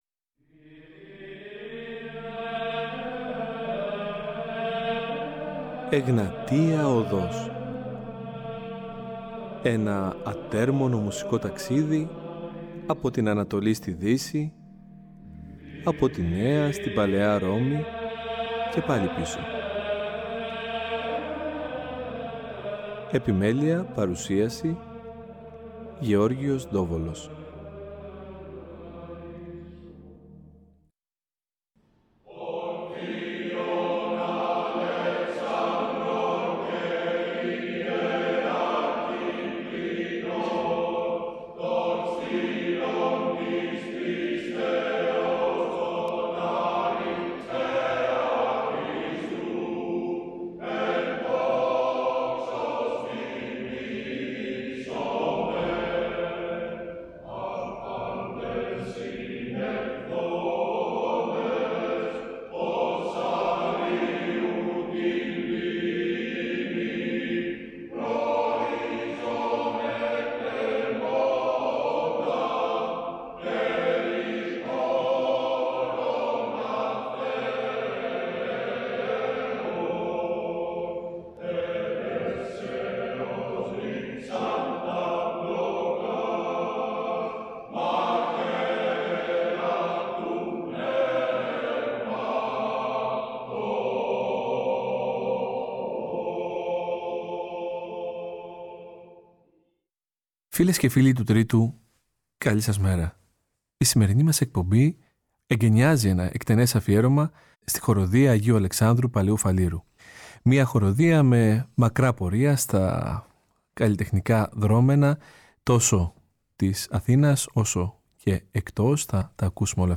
Παράλληλα με τη συνέντευξη, πραγματοποιήθηκε μια μοναδική ηχογράφηση από τη χορωδία με ρεπερτόριο από συνθέτες εκκλησιαστικής πολυφωνικής μουσικής το οποίο η χορωδία αγαπάει να ερμηνεύει.